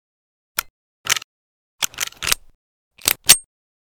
cz52_reload_empty.ogg